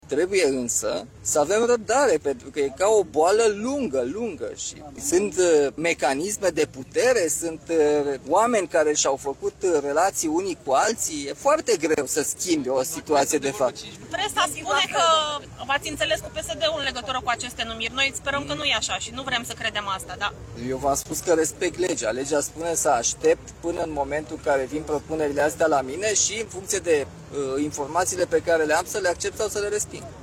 Protest la Cotroceni față de propunerile pentru șefia marilor parchete.
În scurt timp, șeful statului, Nicușor Dan, a ieșit să discute cu protestatarii.